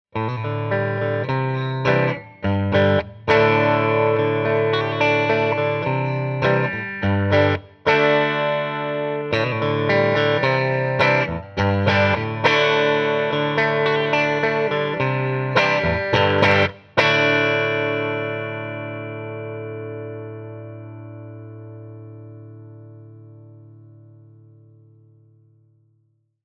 50R and 52T used together (middle position)
a brighter, twangier response to high notes, and the 'twang plate' was changed from zinc to copper plated steel for easier soldering.